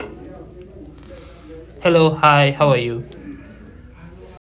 To gain further clarity, we tried passing a pure audio clip of us speaking (no background instruments included).
Pure_Vocal.wav